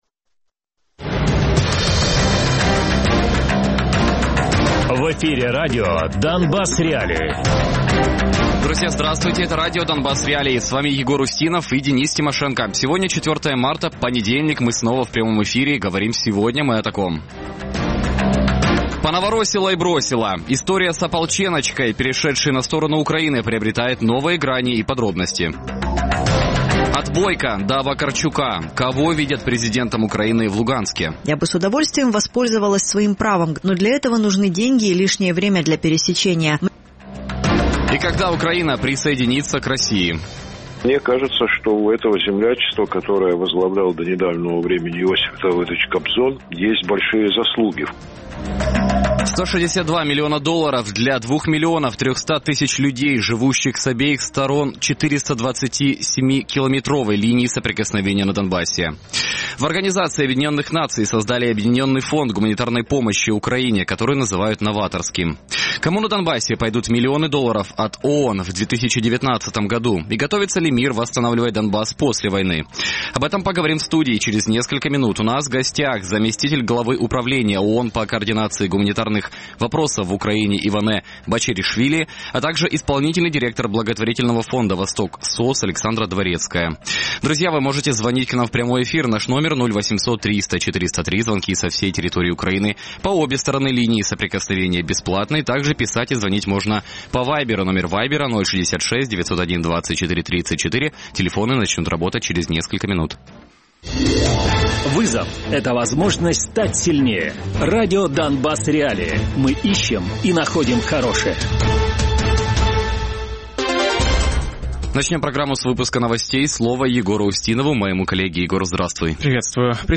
Радіопрограма «Донбас.Реалії» - у будні з 17:00 до 18:00.